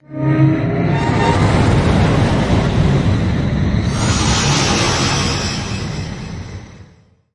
科幻的声音效果 (7)
从ZOOM H6录音机和麦克风Oktava MK01201领域录制的效果，然后处理。
Tag: 未来 托管架 无人驾驶飞机 金属制品 金属 过渡 变形 可怕 破坏 背景 游戏 黑暗 电影 上升 恐怖 开口 命中 噪声 转化 科幻 变压器 冲击 移动时 毛刺 woosh 抽象的 气氛